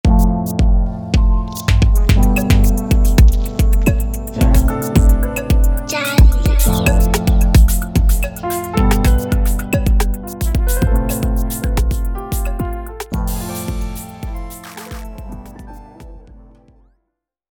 Beats Sound